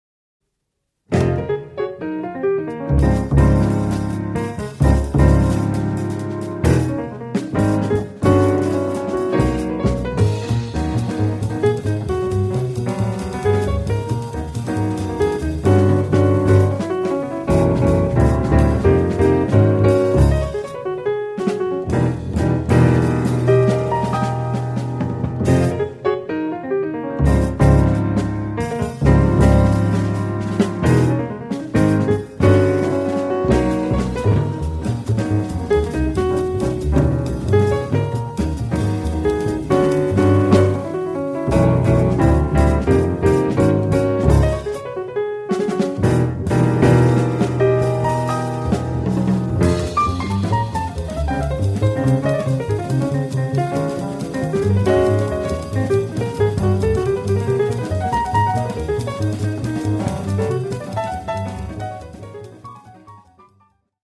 私が一番影響を受けた60年代、70年代のジャズでビルドフォースのサウンドの切れ味の良い曲を作ろうと意図してできた曲です。